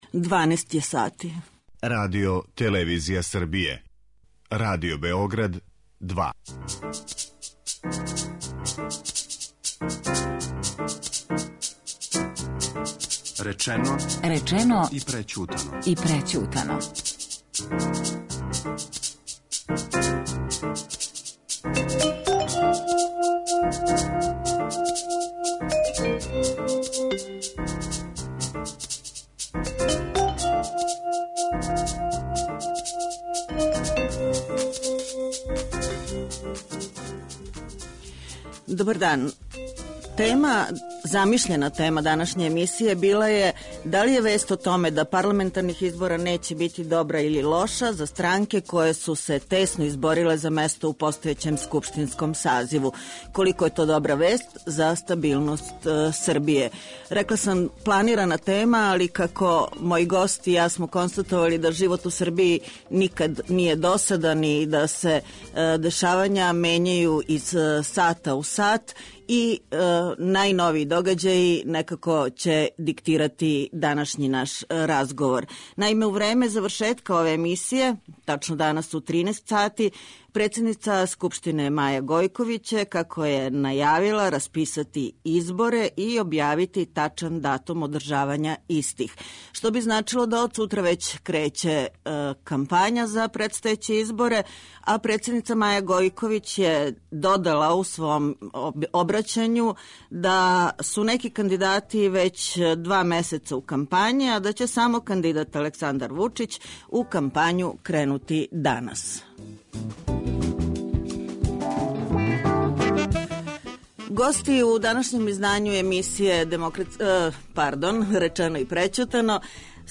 Говоре народни посланици Александар Стевановић (Доста је било), Срђан Ного (Двери) и Марко Ђуришић (СДС).